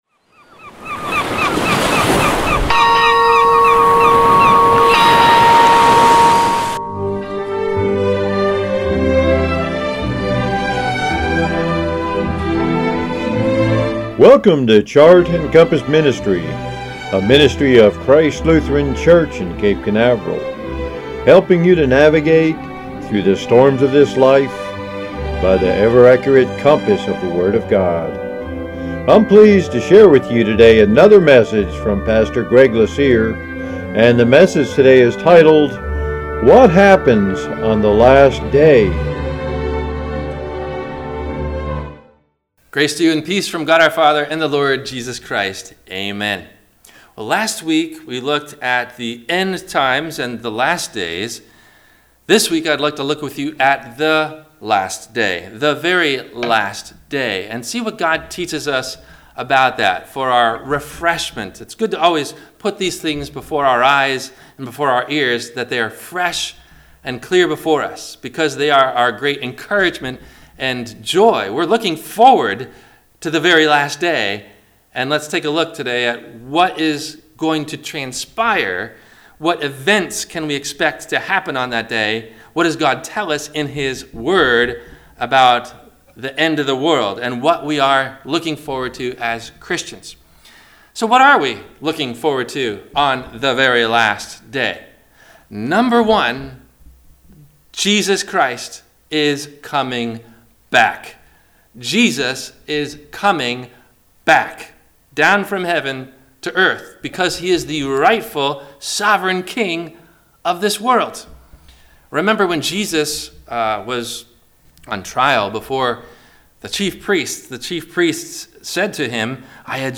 Christian Burnout and Keeping Boundaries – WMIE Radio Sermon – May 18 2020